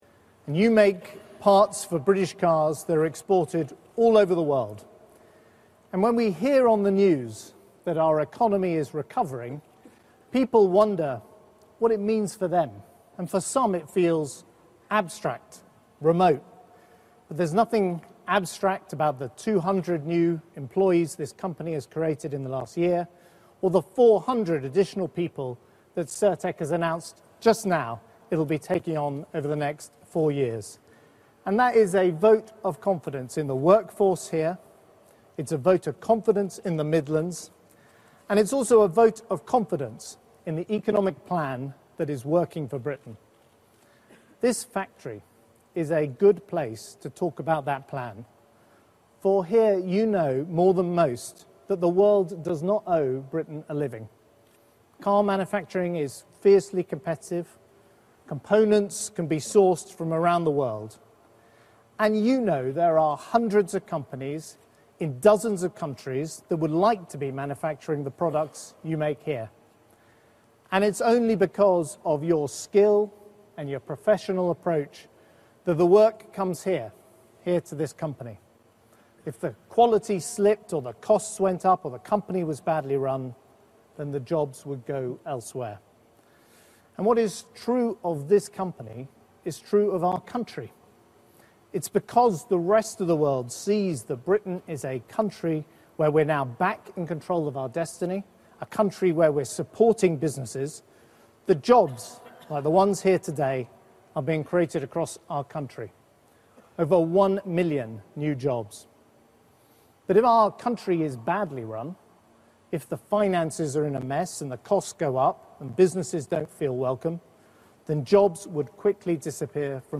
Speech at Coleshill, 6 January 2014